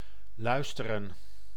Ääntäminen
IPA: /ˈlœy̯s.tə.rə(n)/